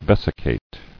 [ves·i·cate]